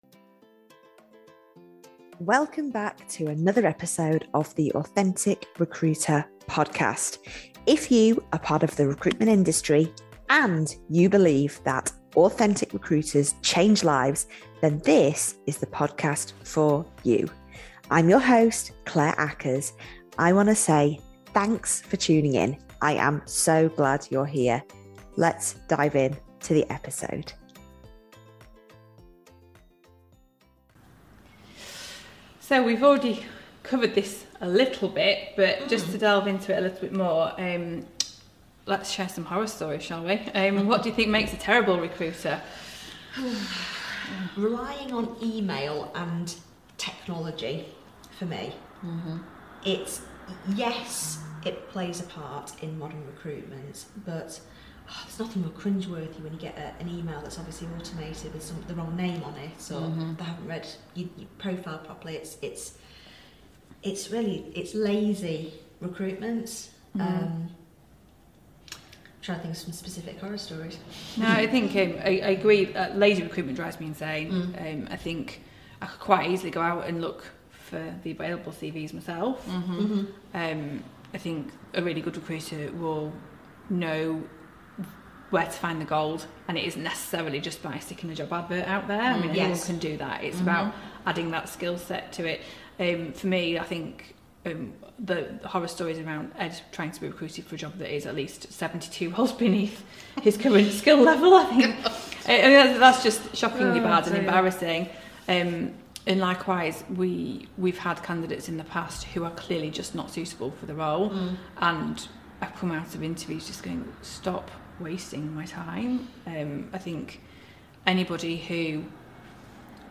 Part II - of this conversation of all things recruitment!